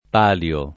p#lLo] s. m.; pl. pali (alla lat. palii) — raro il pl. in ogni caso, e rarissimo nella forma pali (che si confonde col pl. di palo); ammissibile, per chiarezza, la forma palii di regolare apparenza latina (sebbene diversa dal lat. pallia pl. di pallium: etimo, questo, così di palio come di pallio) — anche con P‑ maiusc. come nome della tradizionale gara delle contrade a Siena: la città del P.